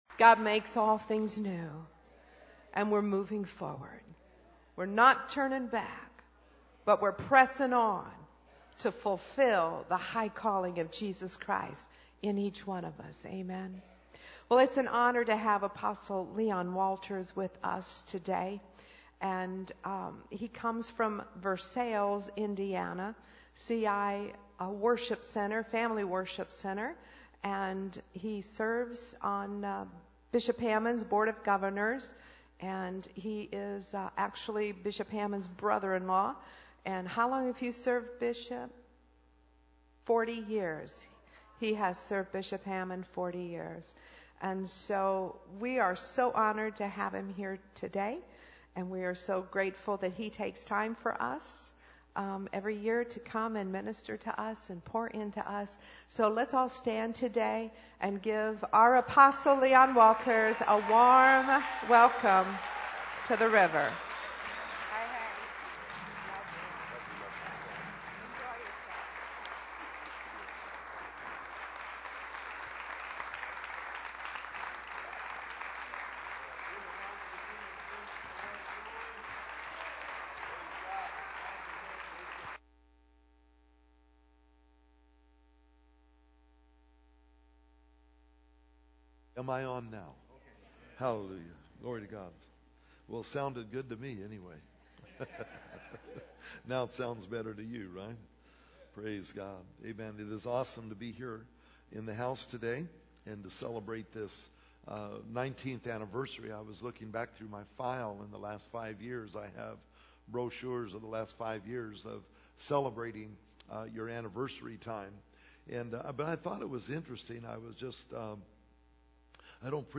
Sermons Archive - Page 51 of 52 - Praysers Ministries, Inc.